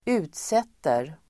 Uttal: [²'u:tset:er]